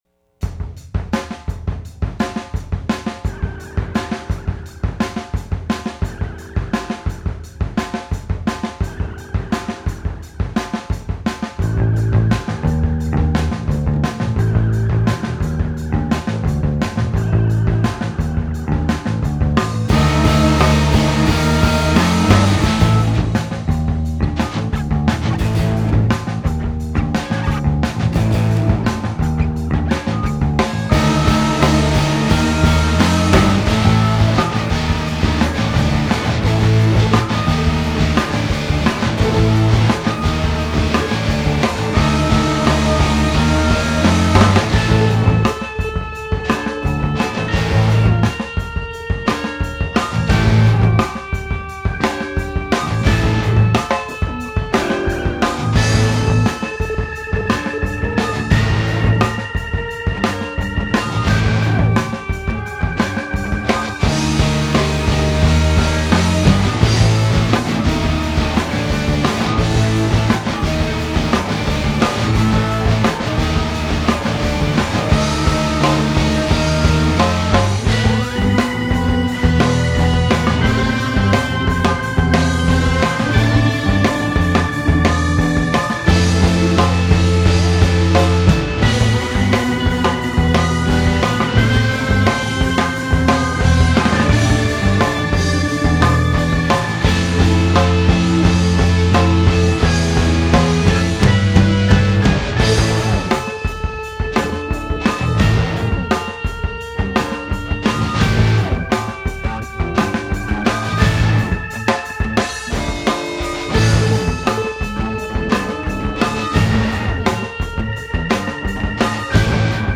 drums
me on everything else. This being the house in Rutherford I rented on my own, I remember running the snake from the living room down to the basement where the drums were and pretty much pieced together the bass and drums of the ‘song’ as we went, and later overdubbing everything else.